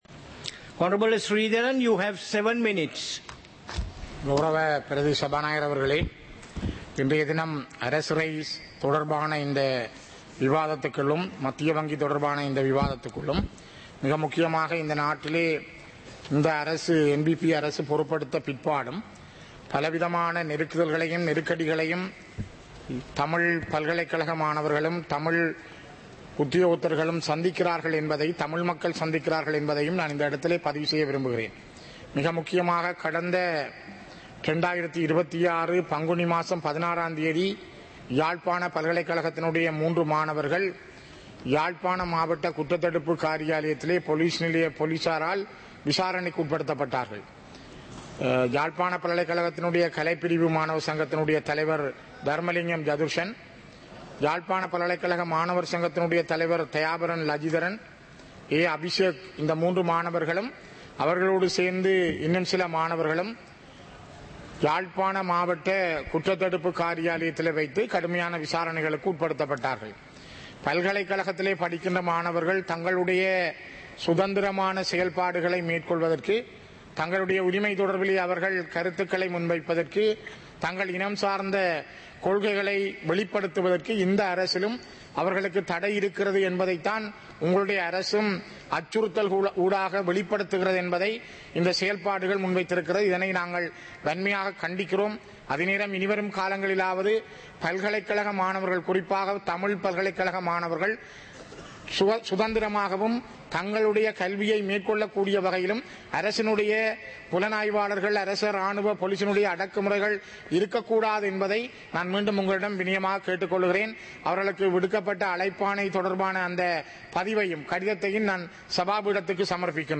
சபை நடவடிக்கைமுறை (2026-04-07)
நேரலை - பதிவுருத்தப்பட்ட